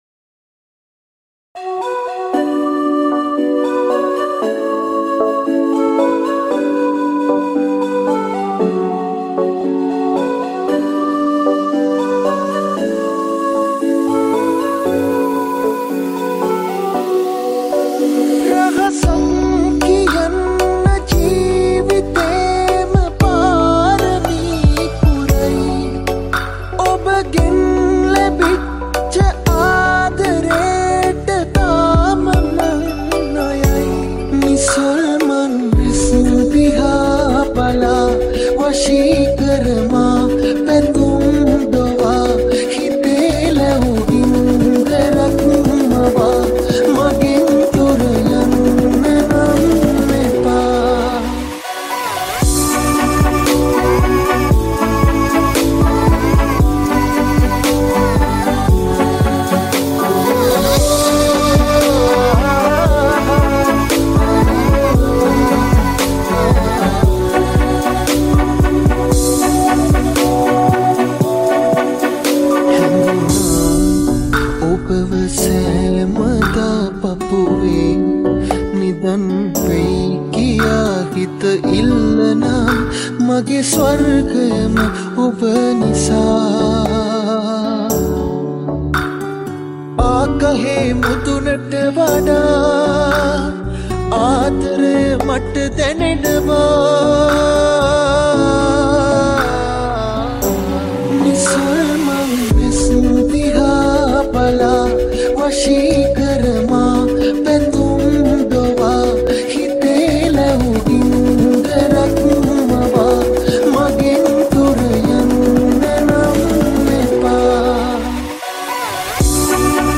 Sinhala Remix Song